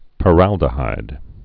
(pə-răldə-hīd)